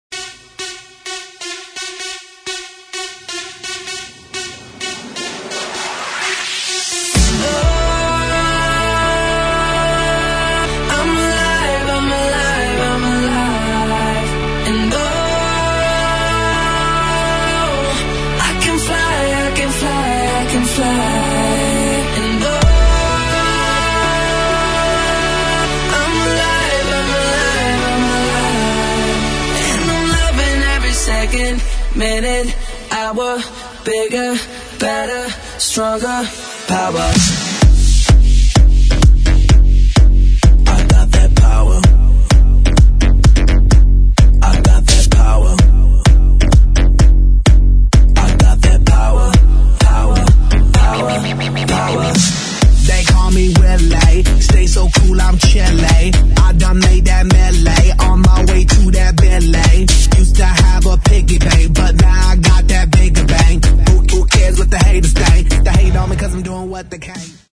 из Клубные